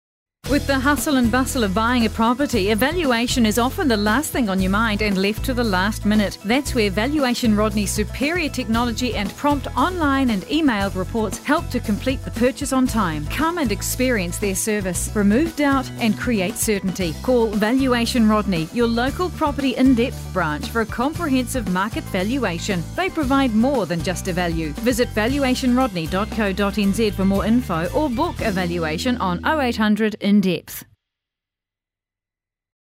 Radio Advertising